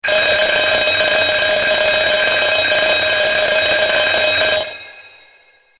BBPro_VintageAlarm.wav